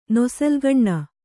♪ nosalgaṇṇa